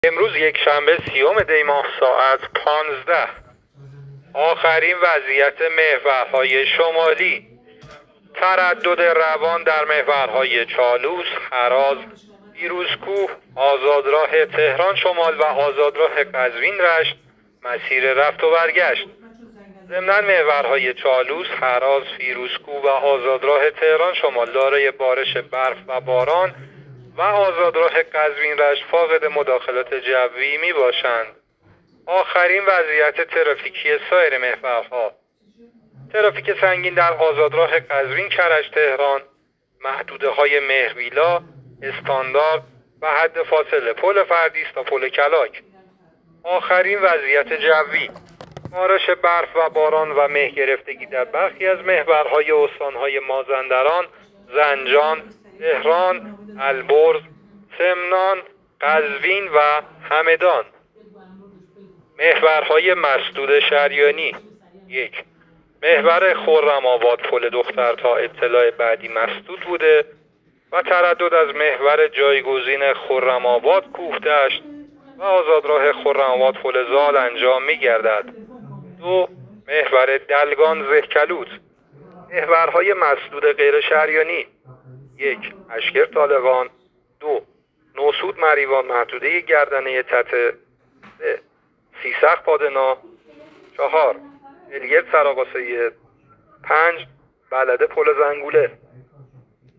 گزارش رادیو اینترنتی از آخرین وضعیت ترافیکی جاده‌ها تا ساعت ۱۵ سی‌ام دی؛